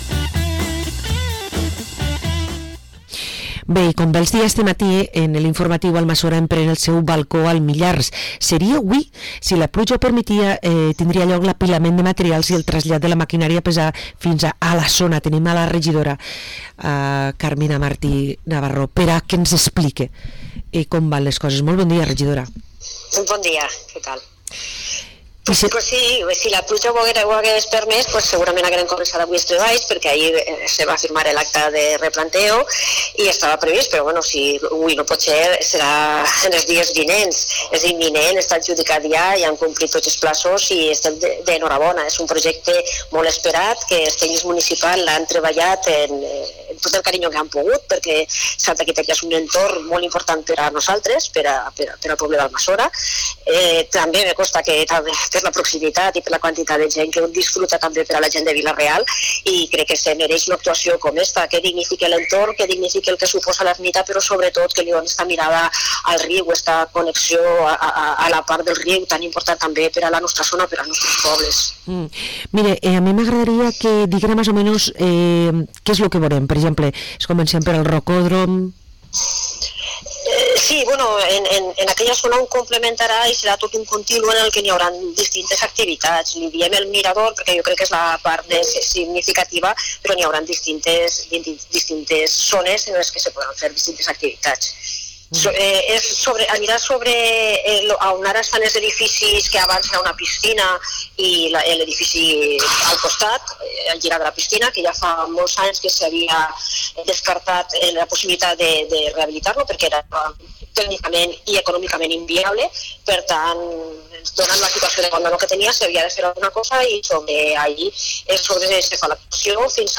Entrevista a Carmina Martinavarro, regidora d´Amassora, ens parla del ‘balcó al Millars’